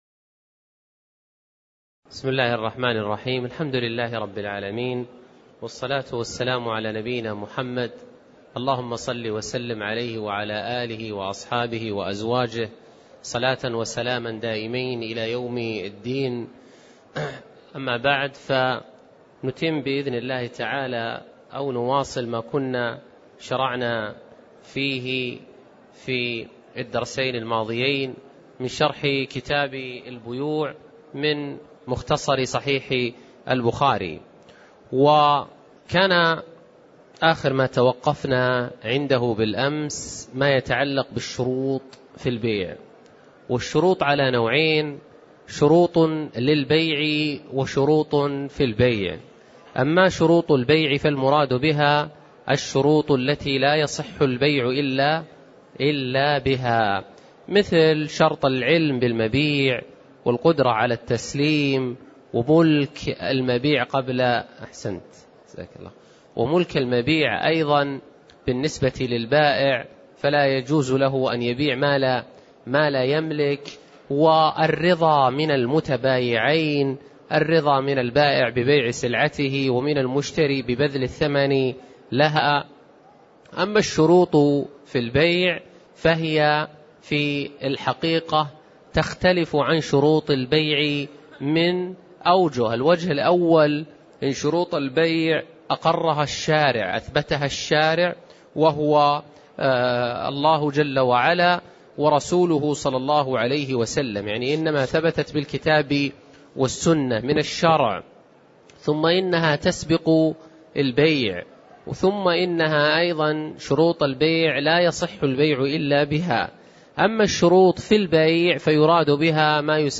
تاريخ النشر ٥ جمادى الأولى ١٤٣٨ هـ المكان: المسجد النبوي الشيخ